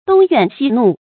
東怨西怒 注音： ㄉㄨㄙ ㄧㄨㄢˋ ㄒㄧ ㄋㄨˋ 讀音讀法： 意思解釋： 謂任意指責別人。